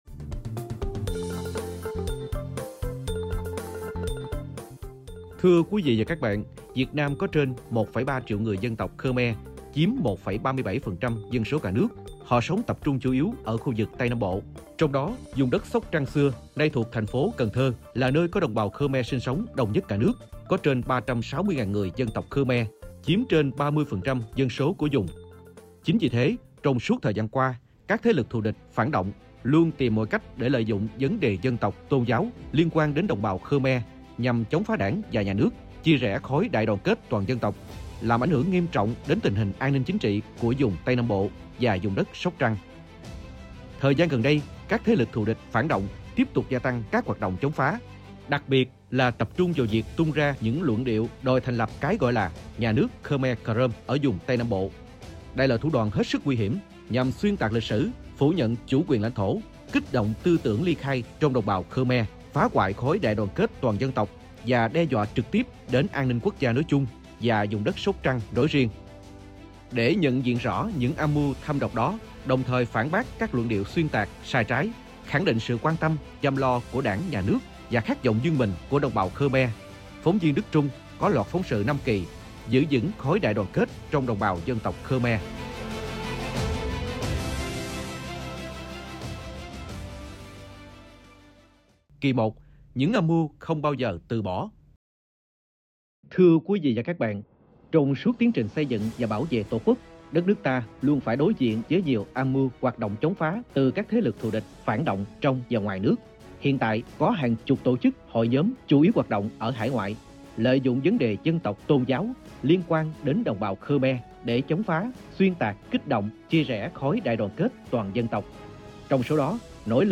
Thưa quý vị và các bạn!